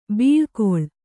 ♪ bīḷkoḷ